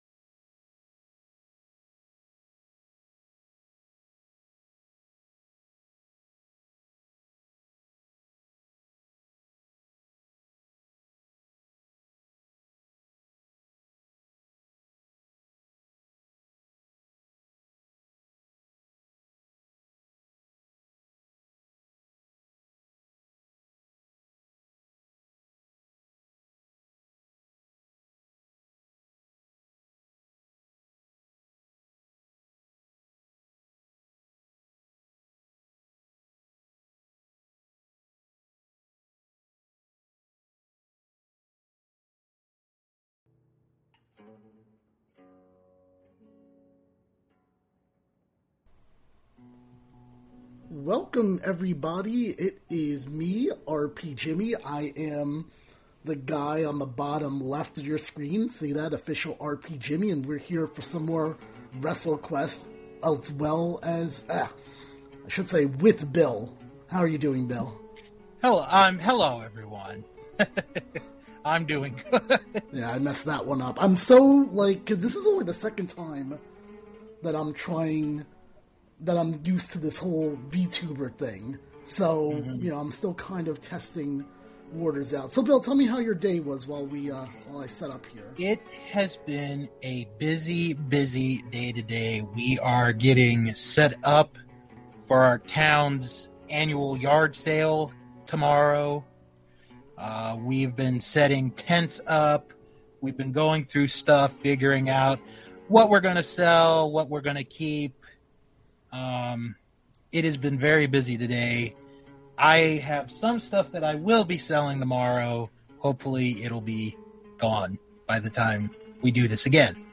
This episode, which was streamed on Twitch before WWE Backlash has the two discussing the show, playing more of the game and much more.